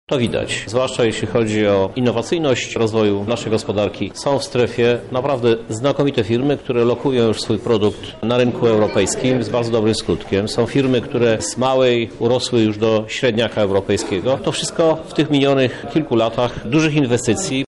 W naszym regionie mamy bardzo dobre firmy – mówi Krzysztof Żuk, prezydent miasta
Gala Przedsiębiorczości